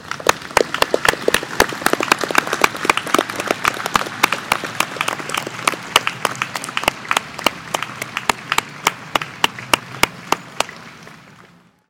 描述：在波兰华沙，一群人在一场钢琴音乐会后鼓掌
Tag: 华沙 钢琴 C河滨 欢呼 欢呼 波兰 人群 掌声